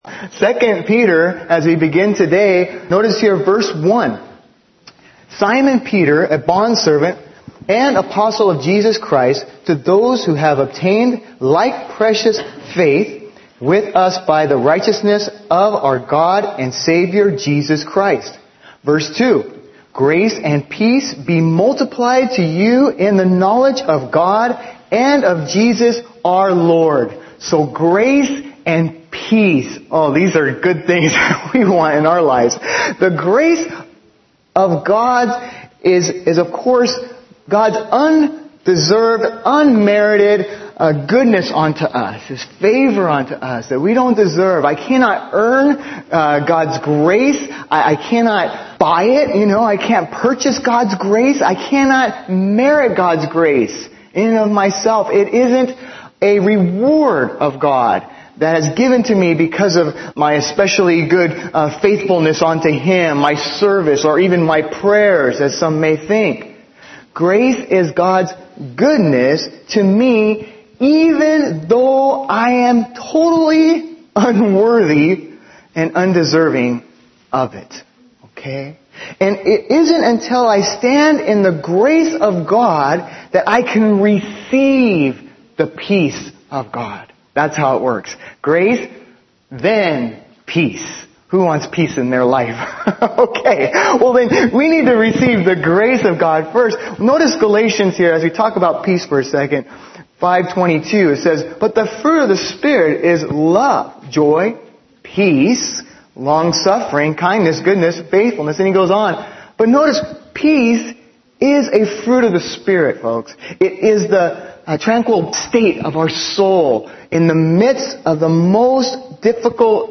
Audio recordings and transcripts of Bible messages shared at OIC.